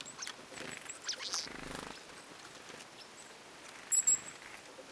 tuollaisia ilmeisesti hömötiaislinnun
niiden sinitiaisten ruokailuäänten kaltaisia ääniä?
ilmeisesti_homotiaisen_tuollaisia_erikoisia_sinitiaismaisia_ns_ruokailuaanten_kaltaisia_aania.mp3